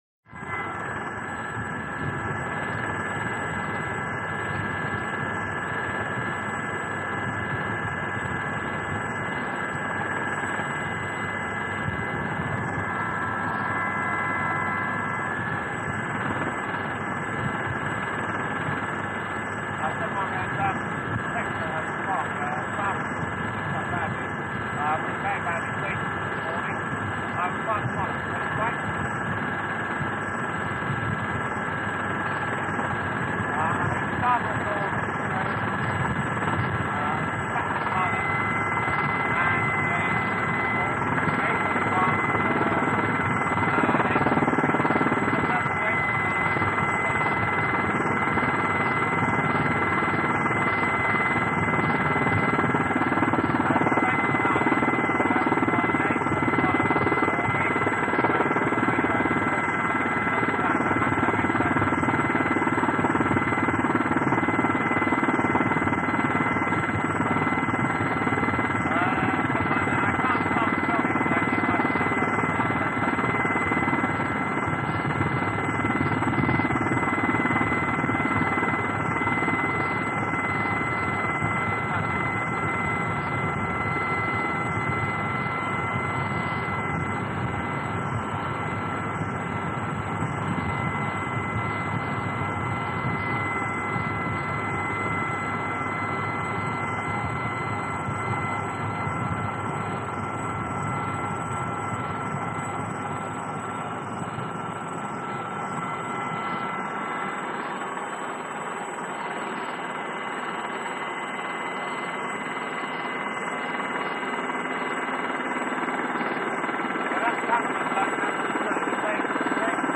HELICOPTER AEROSPATIALE SA-341 GAZELLE: INT: Hover, landing, shutdown. Long hovering.